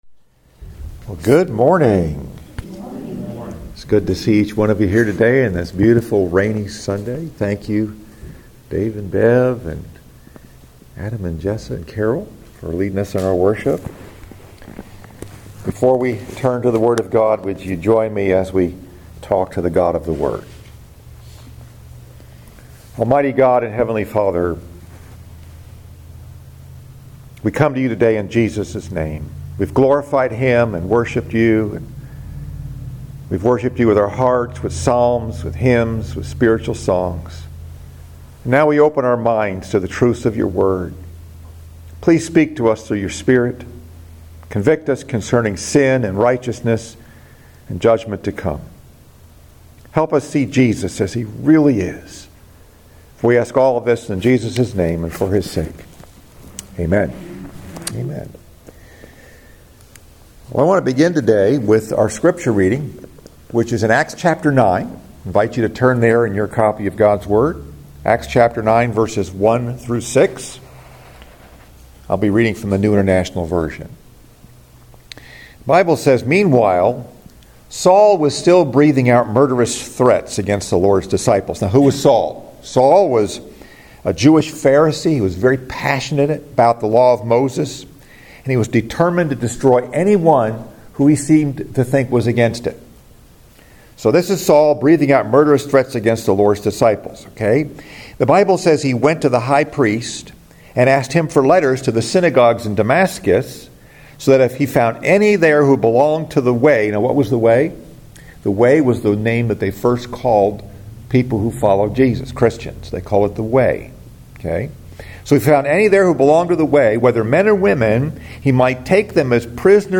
Message: “Defining Moment” Scripture: Acts 9:1-6